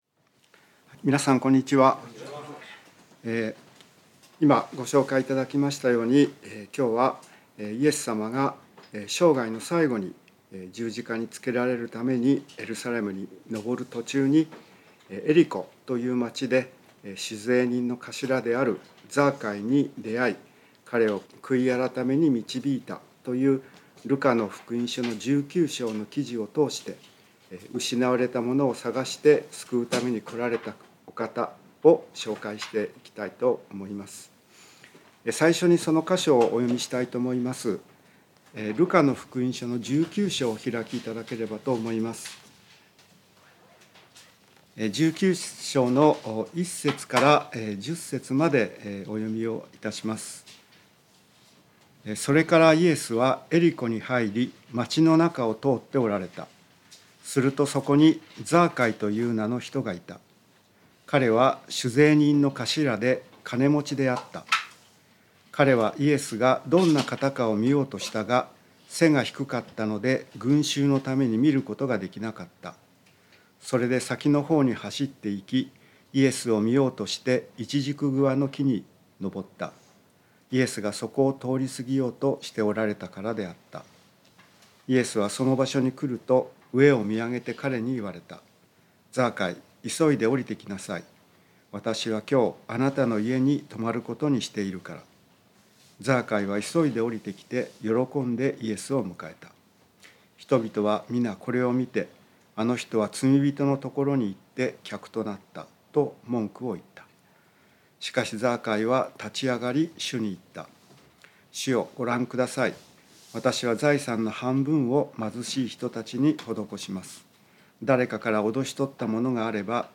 聖書メッセージ No.254